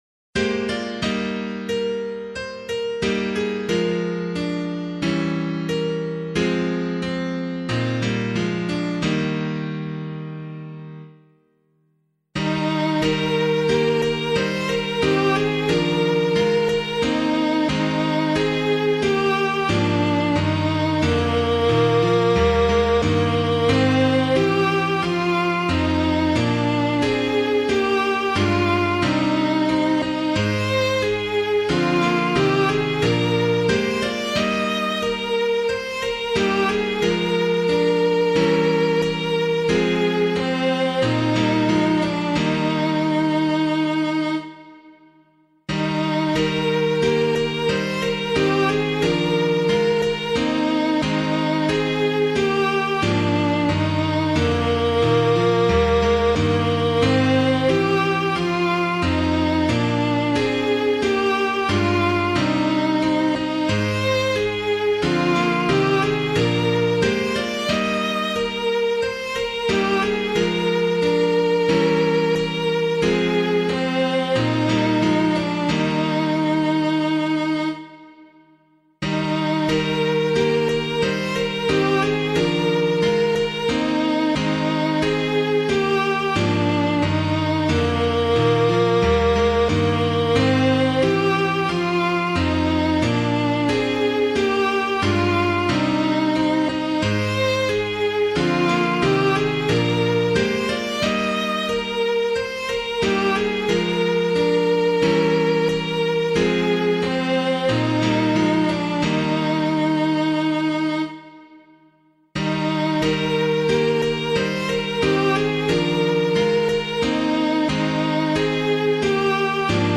Hymn suitable for Catholic liturgy.